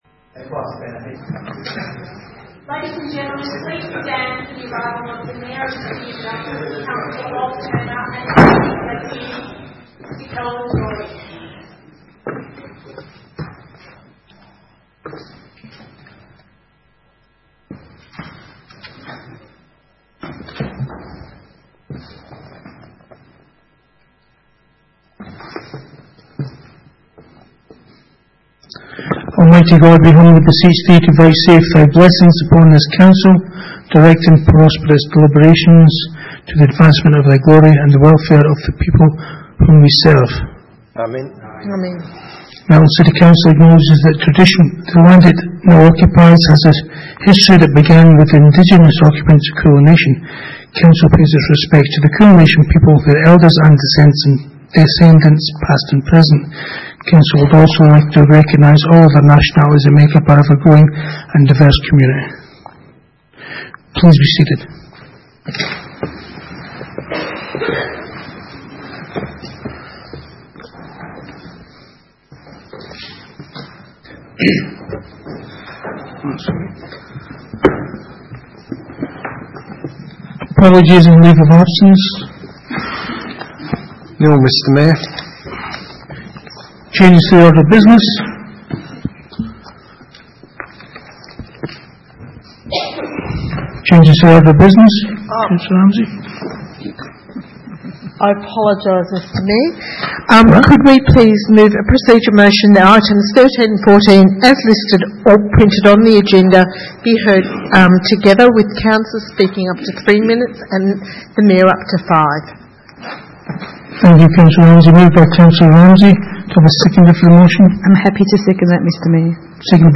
Ordinary Meeting 4 March 2019
Civic Centre, 232 High Street, Melton, 3337 View Map